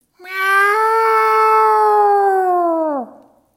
meows-7.mp3